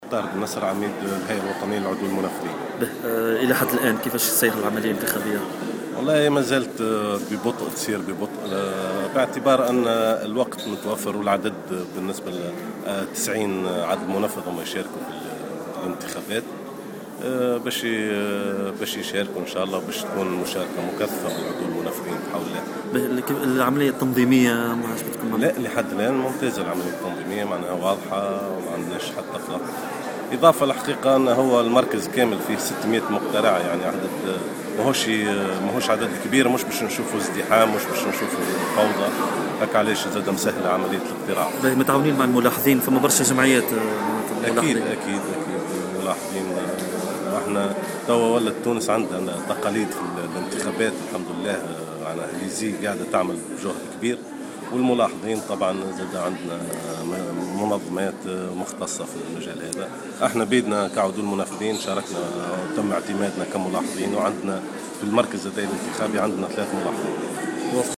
ولاحظ في تصريحات لـ "الجوهرة أف أم" أن العملية الانتخابية على مستوى العدول المنفذين تسير ببطء في بدايتها بالنظر إلى عاملي الوقت والعدد (600 مقترع) متوقعا مشاركة مكثفة من طرف زملائه.